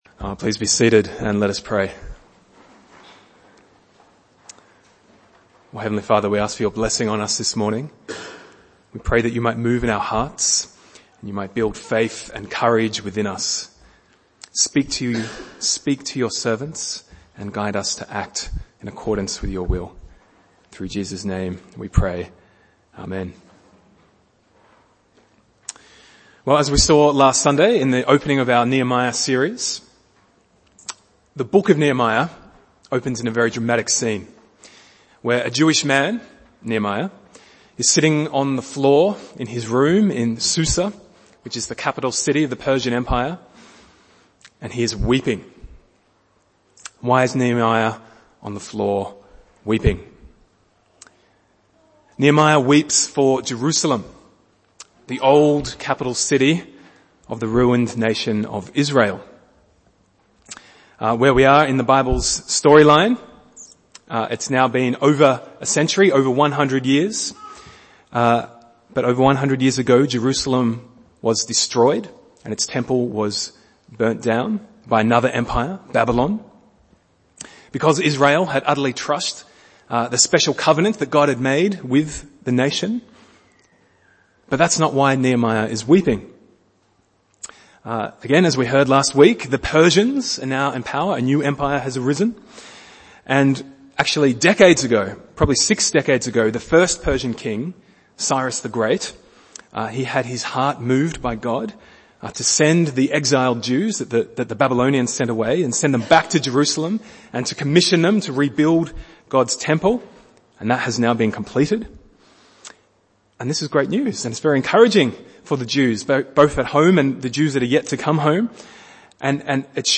Bible Text: Nehemiah 2:1-8 | Preacher